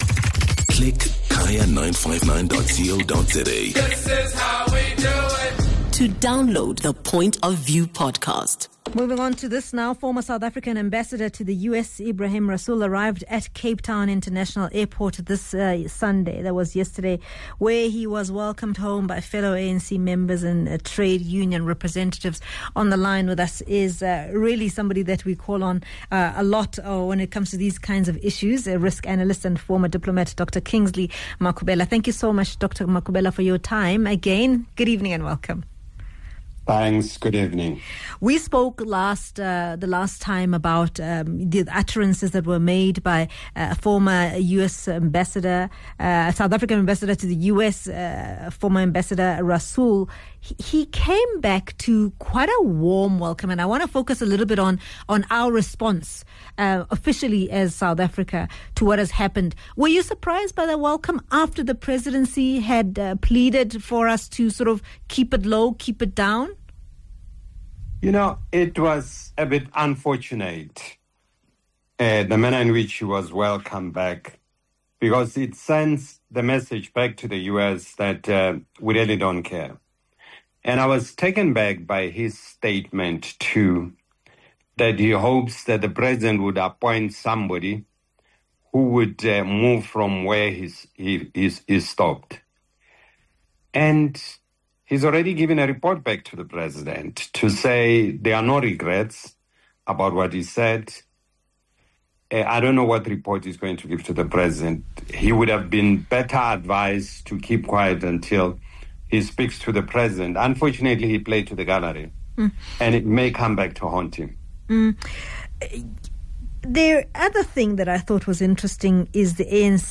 We get some analysis on the latest developments from Risk Analyst and former diplomat